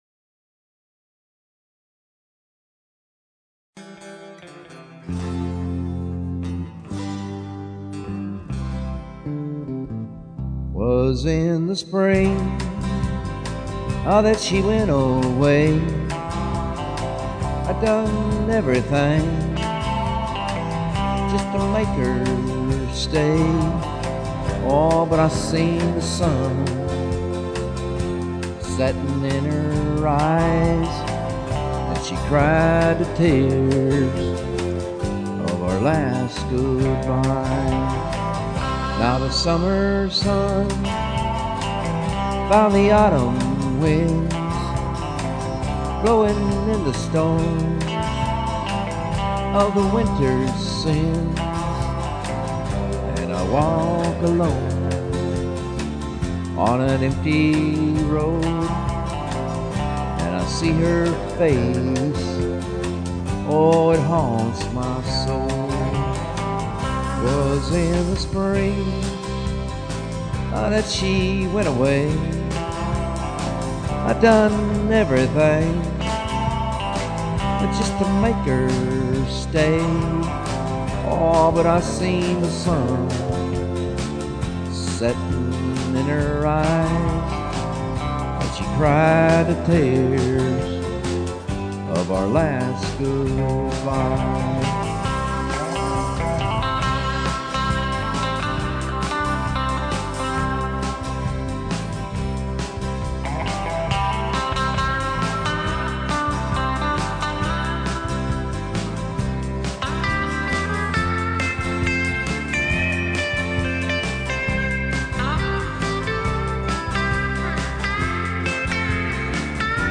is recorded in Hermitage and
Turku Finland Pure Country Music and
and a mix of the 60´s sound
Rhythm Guitars
Lead Guitar
Bass Main
Drums